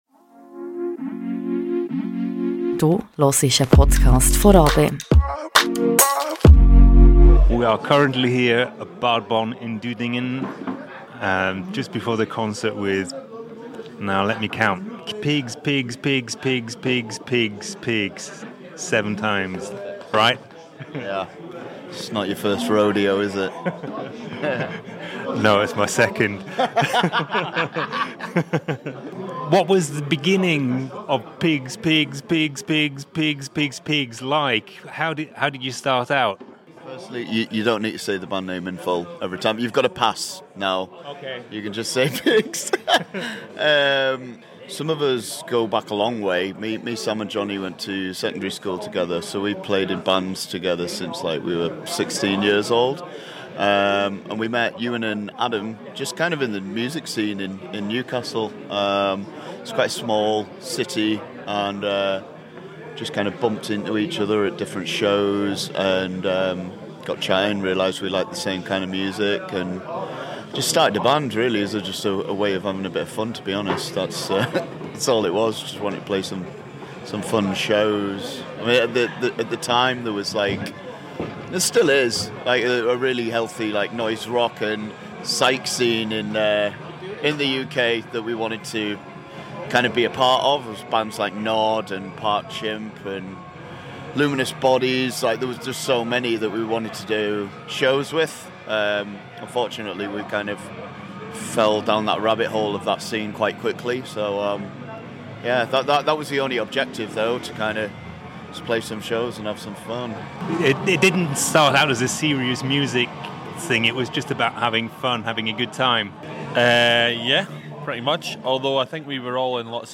Amplifier-Interview with Pigs Pigs Pigs Pigs Pigs Pigs Pigs ~ Radio RaBe Podcast
Pigsx7 have recently released their latest album Death Hilarious. On the tour to promote the release of the album they stopped in Bad Bonn for a legendary show and a friendly chat.